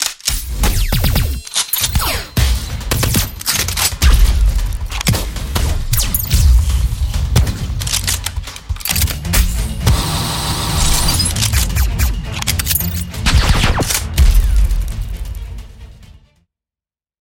Unity – 科幻音效素材 Ultimate Sci-Fi SFX Bundle
– 科幻武器声音专业版
– 外星人声音专业版
– 科幻氛围专业版
– Impacts & Sweeps Pro